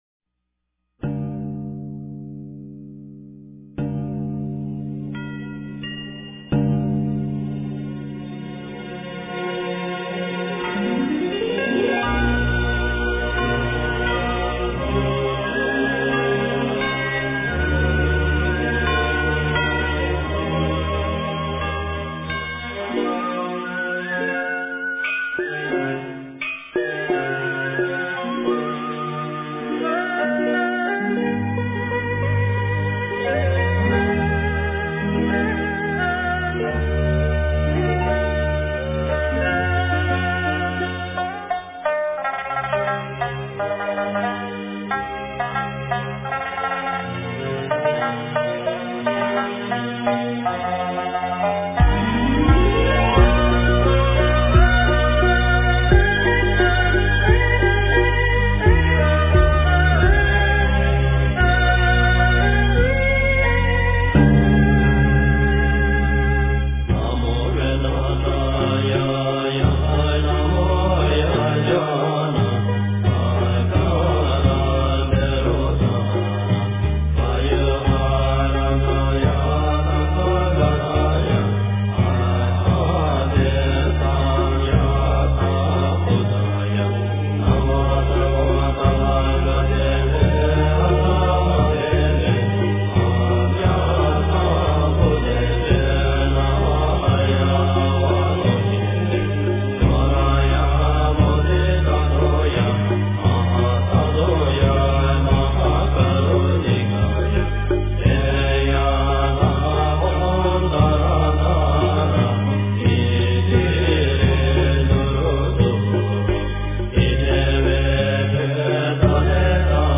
诵经
佛音 诵经 佛教音乐 返回列表 上一篇： 心经-诵读 下一篇： 大悲咒-念诵 相关文章 楞严咒